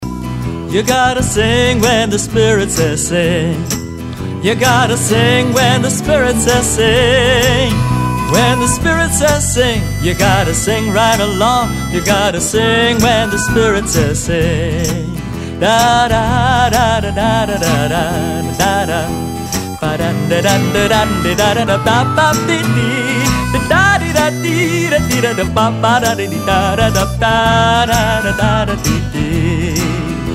Folk Song Lyrics